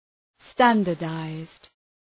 Shkrimi fonetik{‘stændər,daızd}